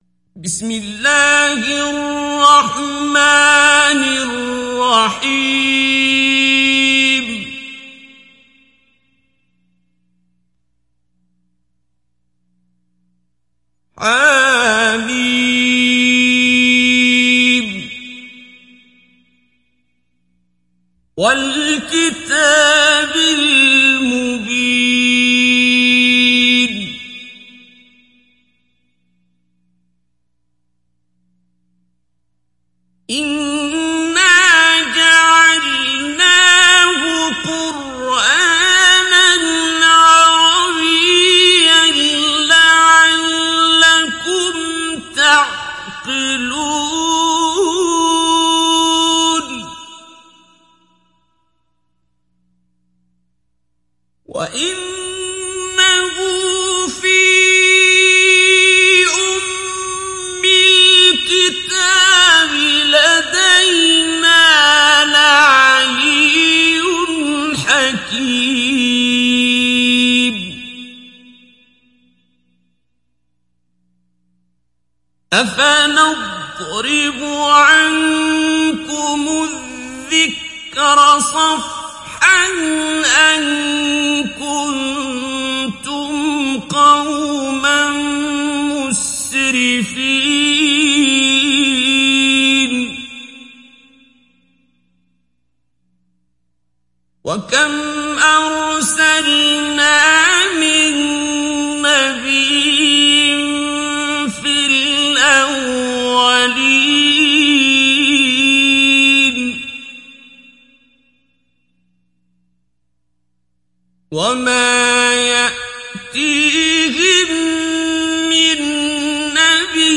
İndir Zuhruf Suresi Abdul Basit Abd Alsamad Mujawwad